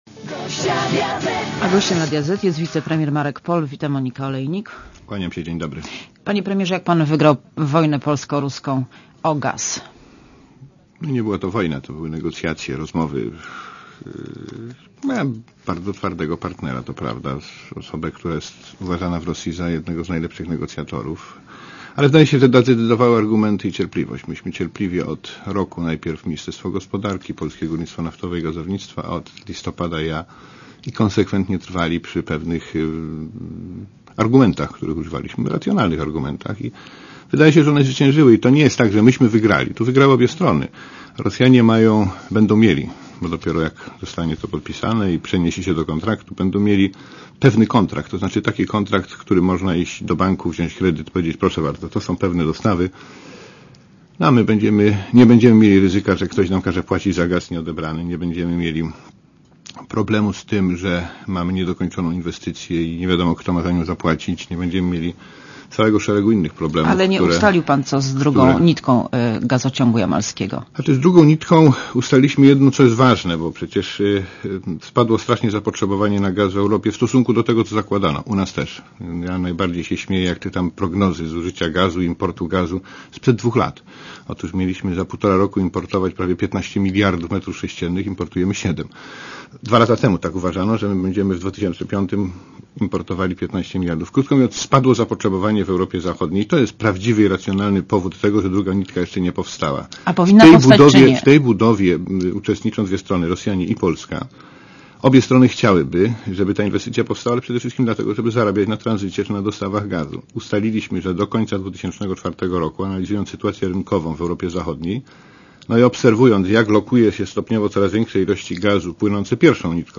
Monika Olejnik rozmawia z Markiem Polem - ministrem infrastruktury